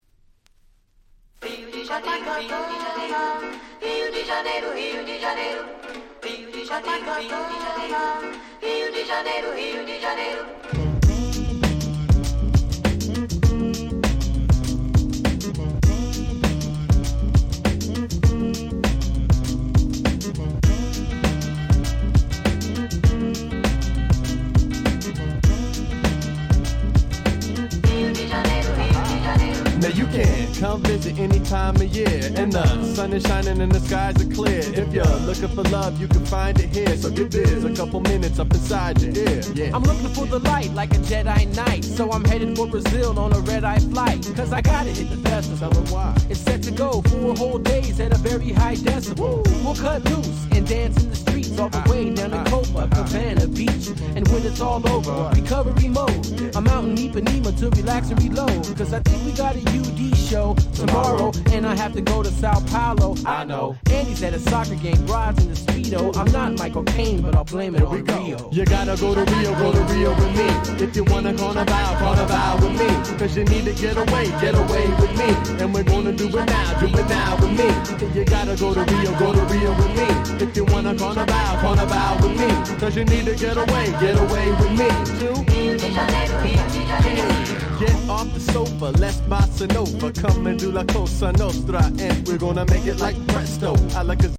00's Underground Hip Hop Classics !!
タイトル通りブラジルの風を感じる軽快なClubチューンです！